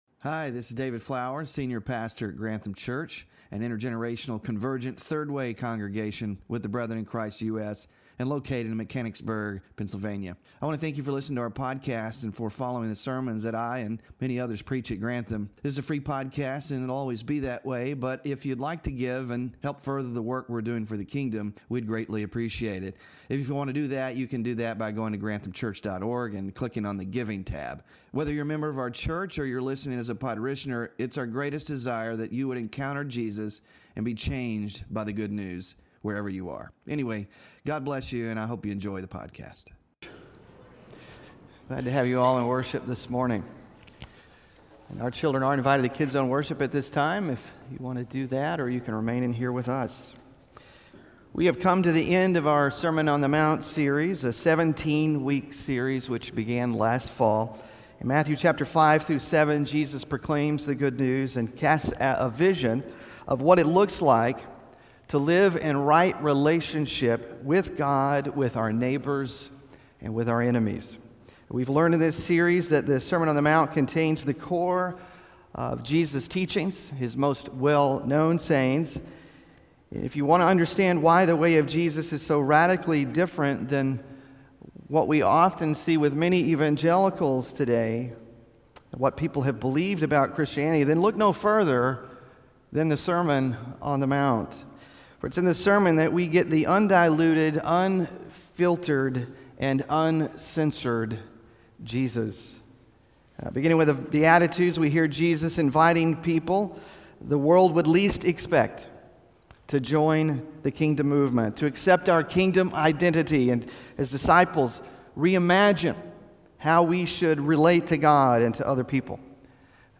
Sermon Focus: In his concluding words from the Sermon on the Mount, Jesus says that we have a choice to make. We can build our lives on the solid rock of his teachings or build our lives on sinking sand.